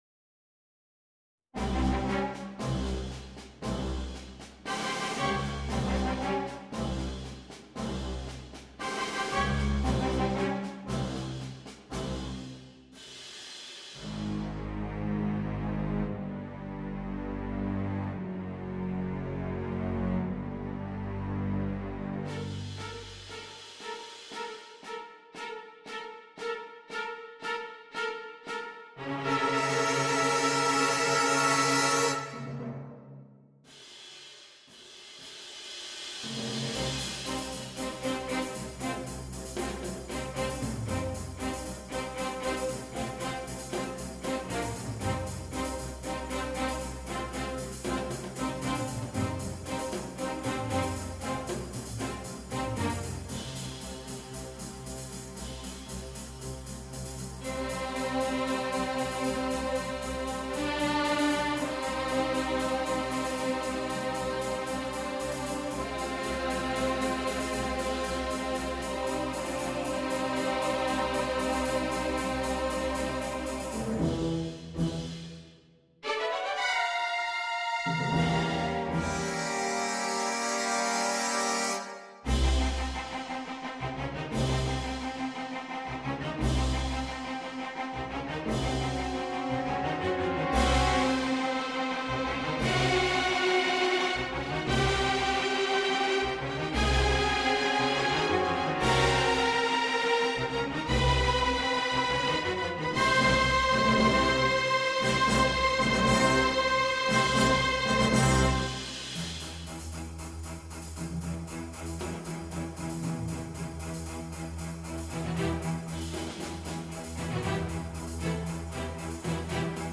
на симфонический оркестр
Bass, Violin I, Violin II, Viola, Violoncello, Contrabass.